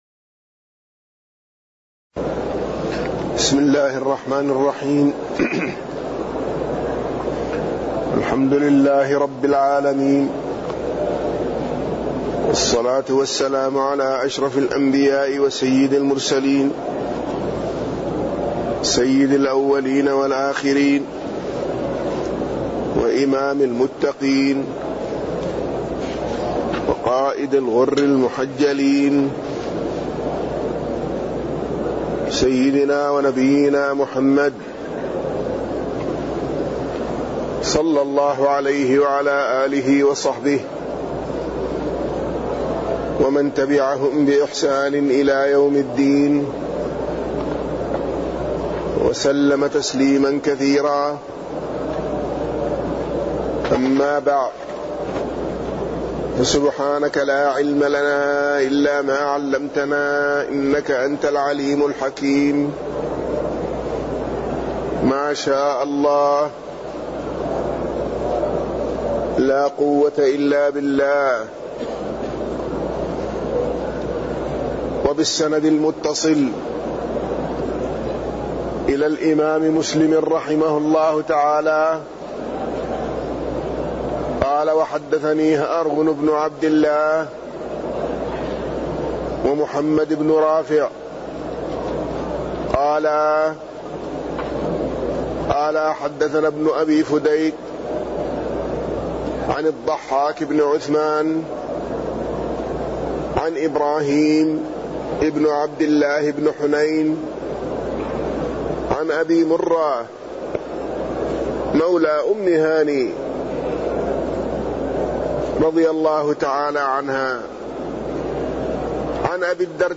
تاريخ النشر ٢٠ شعبان ١٤٣٠ هـ المكان: المسجد النبوي الشيخ